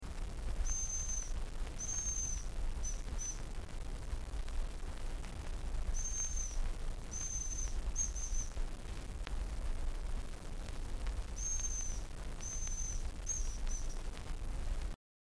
Звуки рябчика
Имитация голоса рябчика искусственная